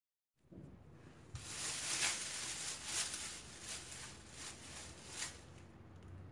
Tag: 环境 atmophere 记录